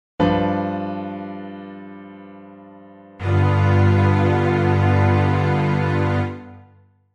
This chord with the Major Third and the minor seventh is called a Seven Chord.
Click to hear a G7 Chord.
g7_chord.mp3